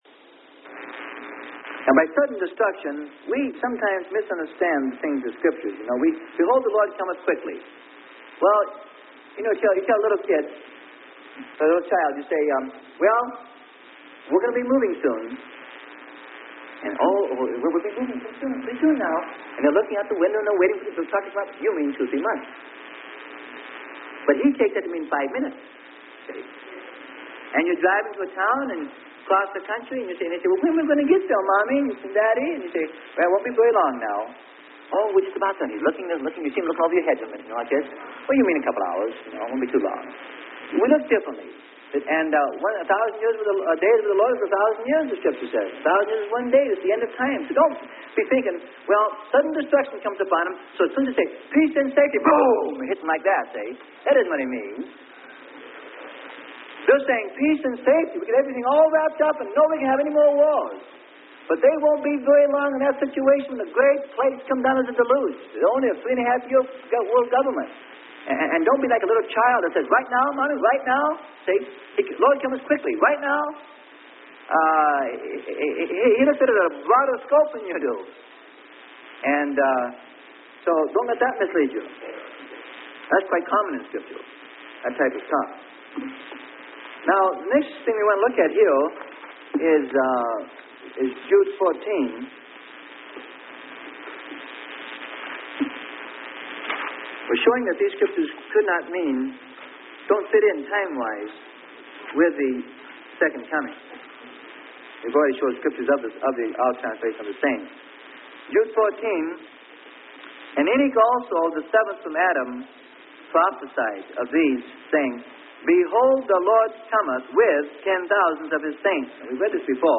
Sermon: Rapture - Part 6 - Freely Given Online Library